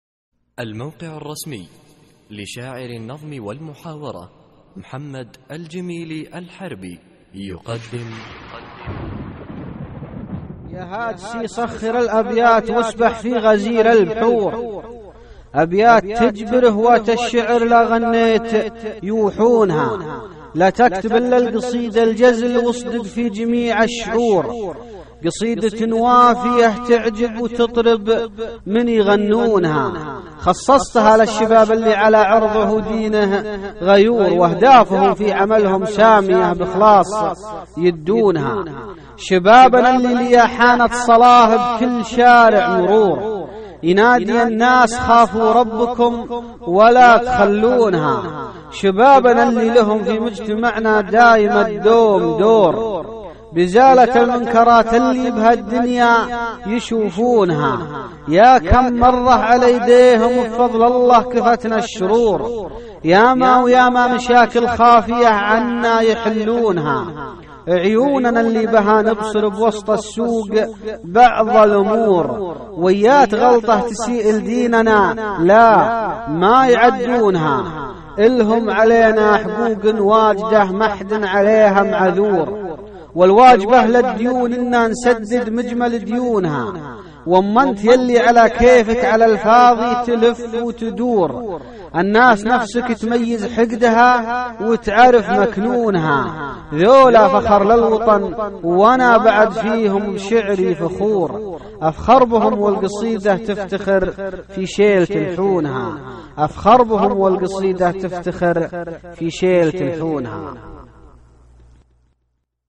القصـائــد الصوتية
اسم القصيدة : الهيئة ~ إلقاء